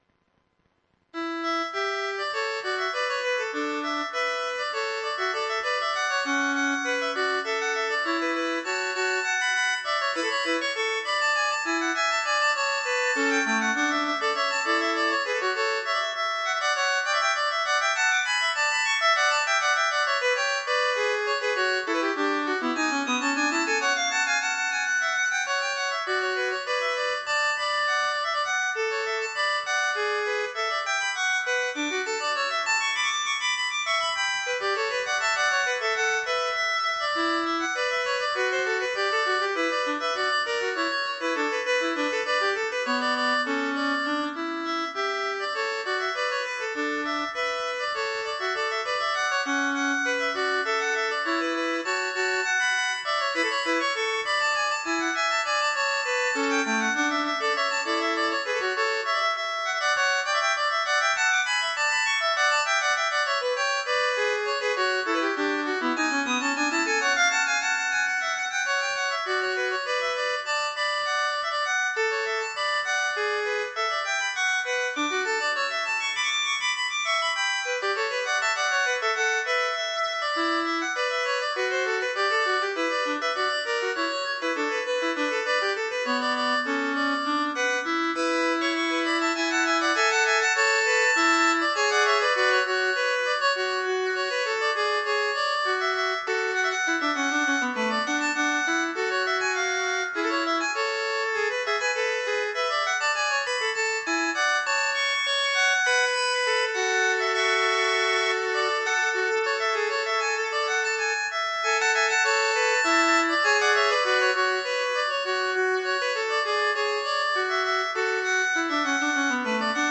duettini_for_2violins_no1.mp3